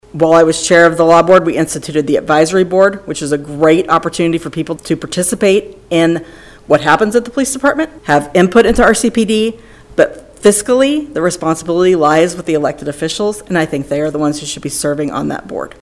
Multiple community members proposed a shift in the constitution of the Riley County Law Board at Tuesday’s Manhattan City Commission meeting.